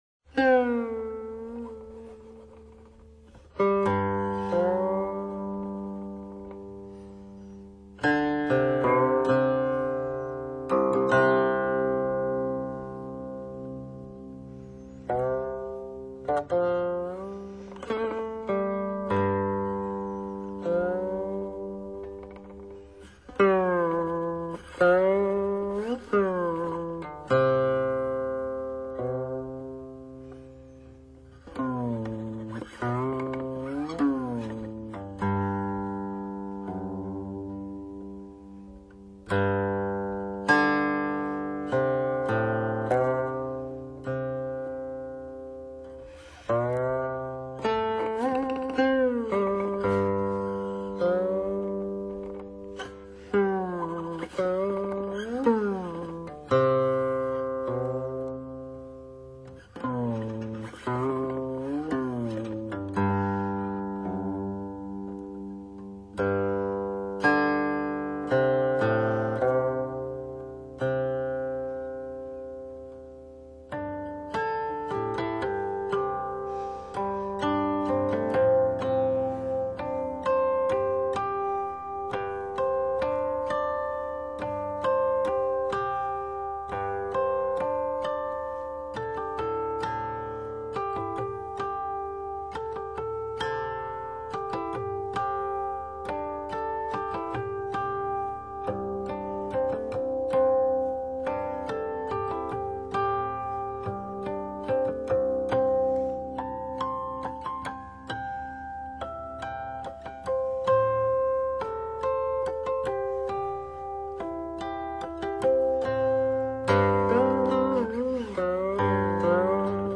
古琴